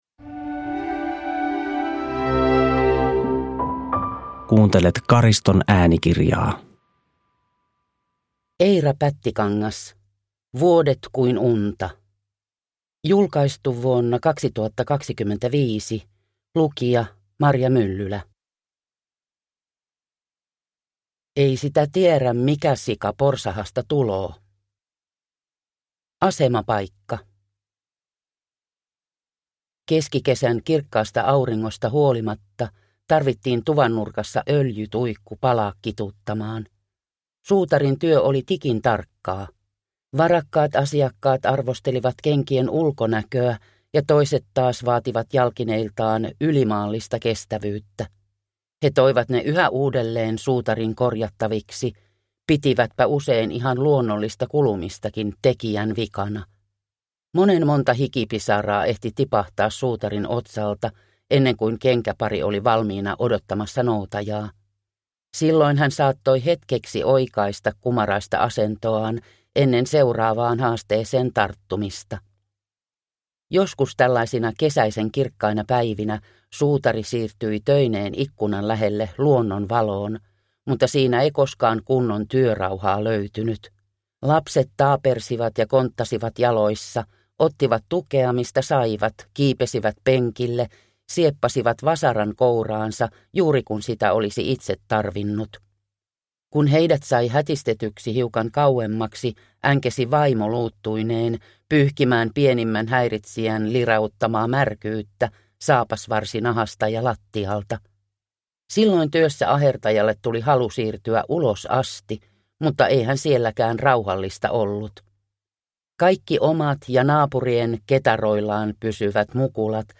Vuodet kuin unta (ljudbok) av Eira Pättikangas